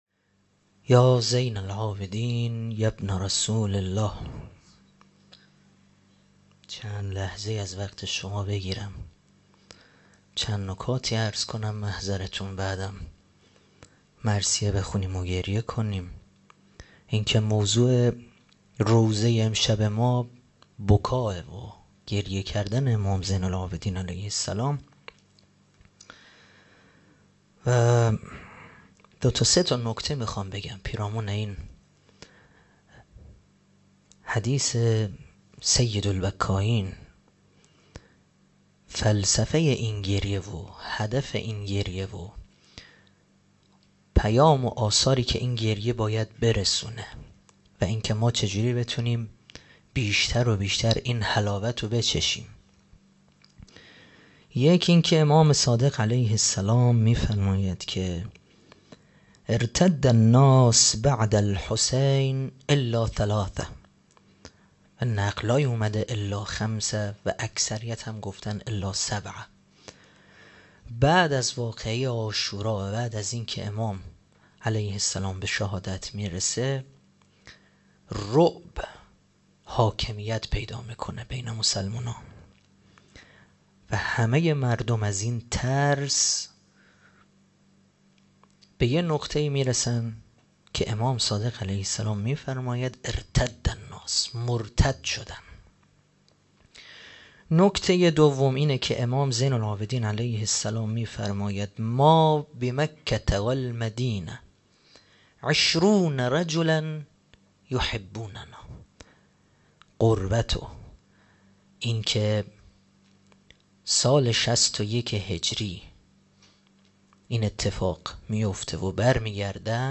روضه | هر روز پای هر محنت گریه می‌کنم